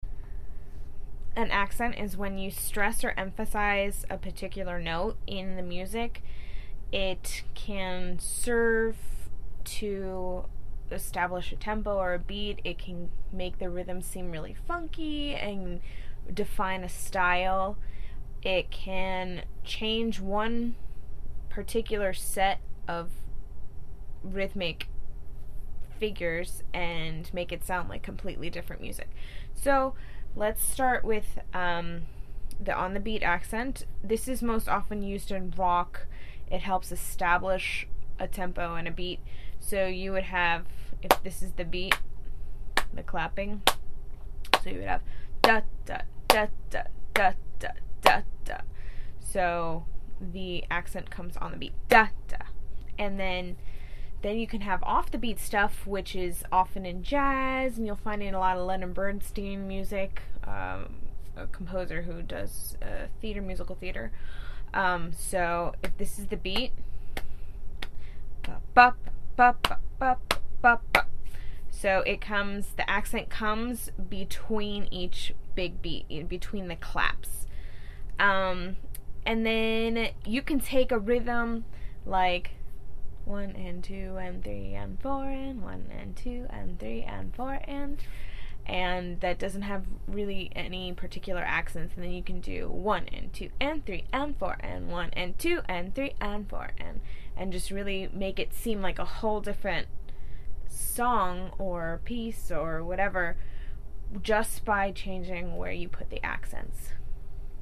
• On the beat accents – often used for rock. They come on the beat or pulse.
• Off the beat accents – often used for jazz. These avoid sounding on the beat and give the music a funky feel.
mekdost-accents-1.mp3